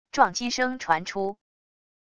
撞击声传出wav音频